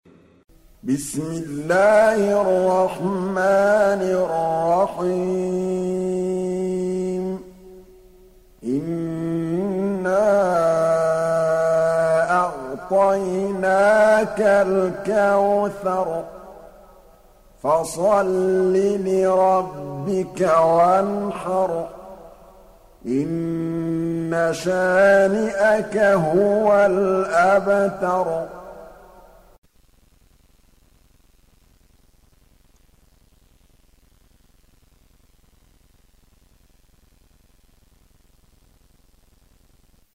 تحميل سورة الكوثر mp3 بصوت محمد محمود الطبلاوي برواية حفص عن عاصم, تحميل استماع القرآن الكريم على الجوال mp3 كاملا بروابط مباشرة وسريعة